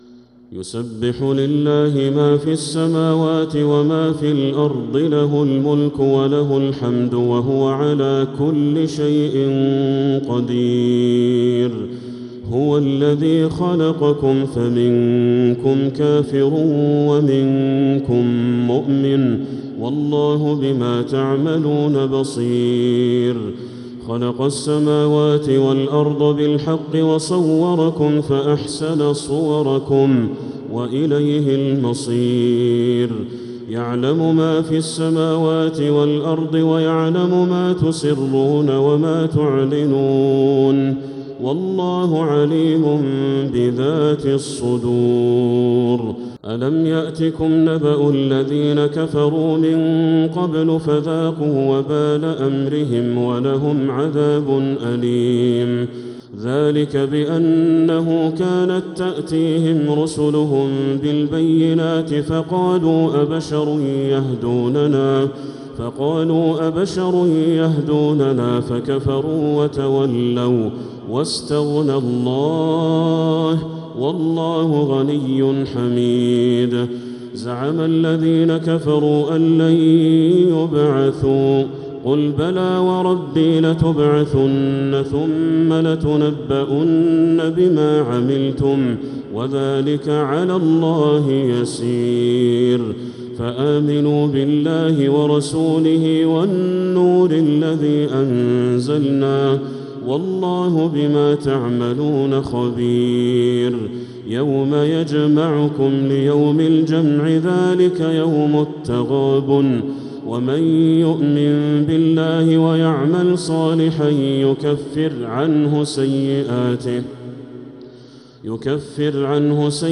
سورة التغابن | مصحف تراويح الحرم المكي عام 1446هـ > مصحف تراويح الحرم المكي عام 1446هـ > المصحف - تلاوات الحرمين